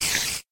mob / spider / say2.ogg